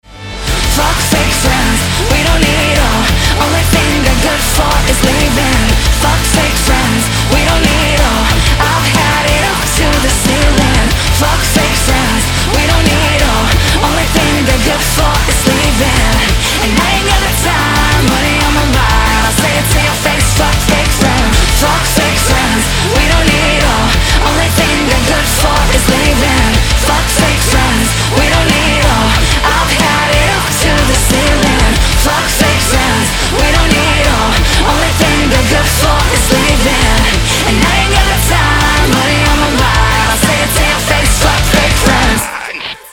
• Качество: 320, Stereo
громкие
Hard rock
Rock cover
Рок-кавер